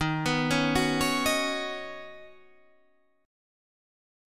Ebm7#5 chord